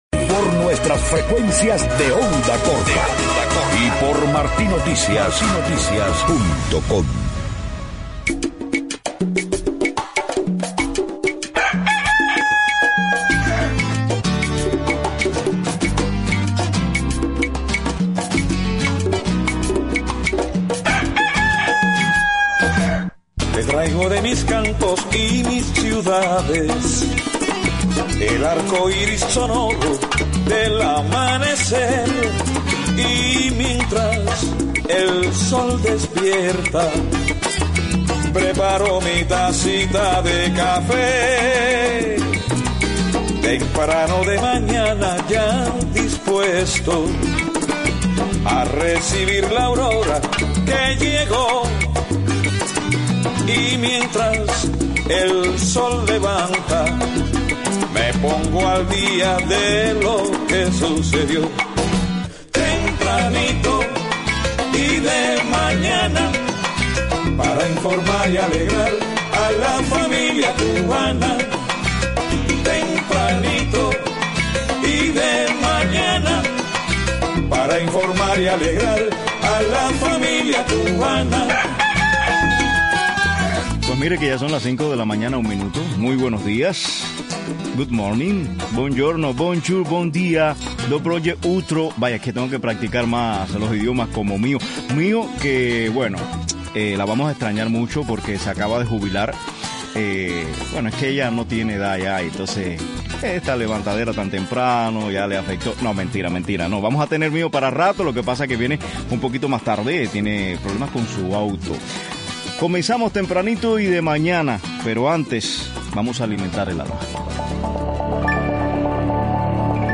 Desde sus inicios como estudiante hasta la actualidad. Una plática repleta de anécdotas que disfrutamos.